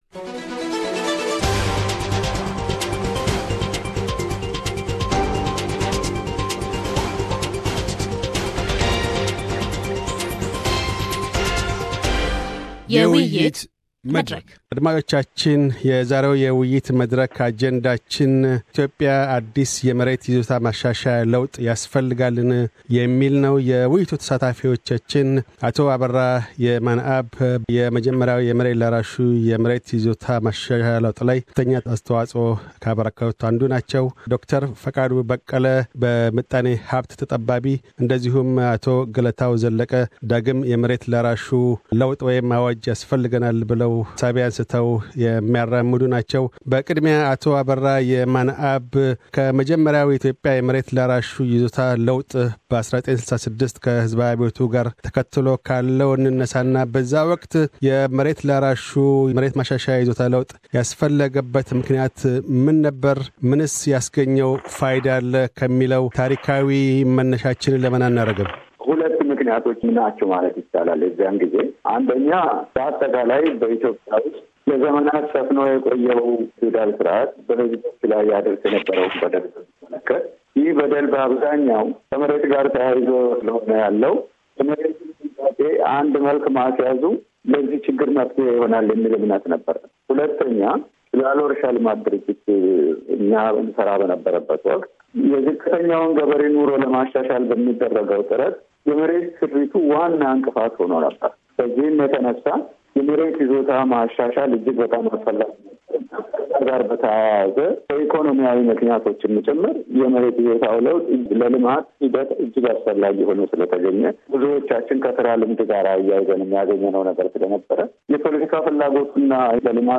A Panel discussion: Does Ethiopia need a new land reform? – Pt 1